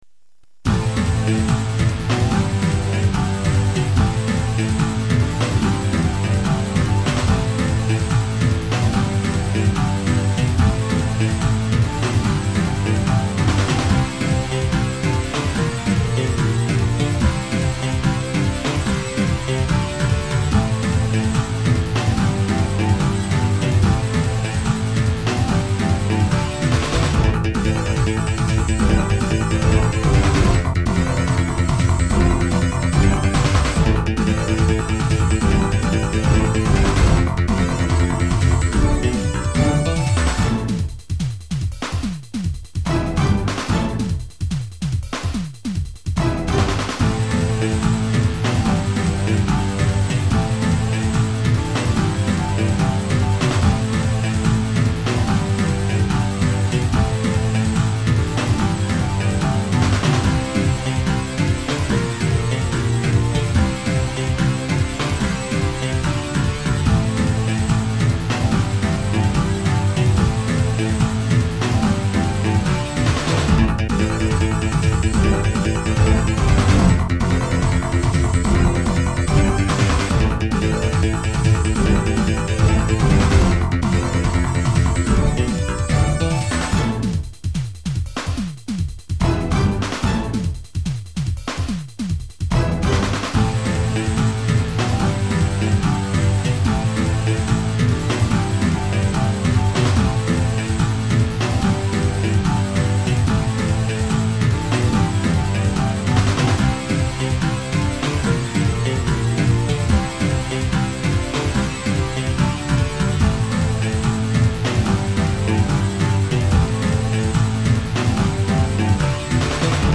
※データは全てSC-88Pro専用です